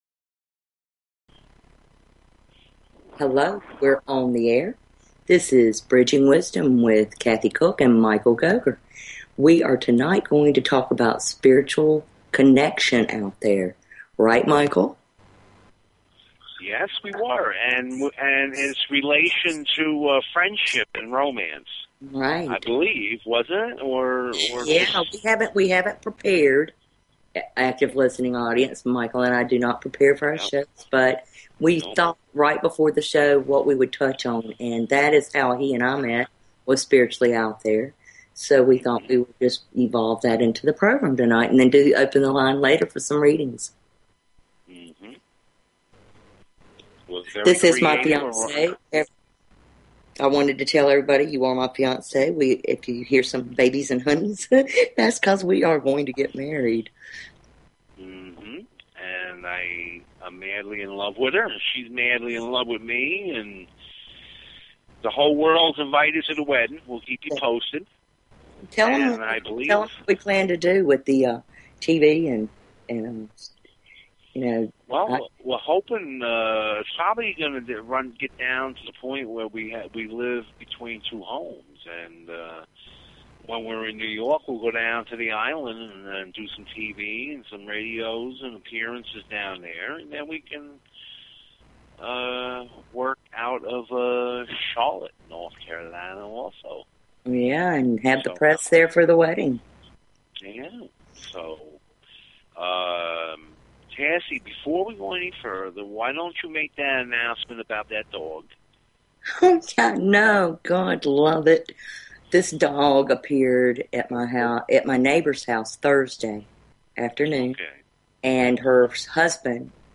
Talk Show Episode, Audio Podcast, Bridging_Wisdom and Courtesy of BBS Radio on , show guests , about , categorized as